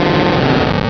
Cri d'Abo dans Pokémon Rubis et Saphir.